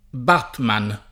vai all'elenco alfabetico delle voci ingrandisci il carattere 100% rimpicciolisci il carattere stampa invia tramite posta elettronica codividi su Facebook Batman [ingl. bä ^ tmän ; italianizz. b # tman ] pers. m. — personaggio di fumetti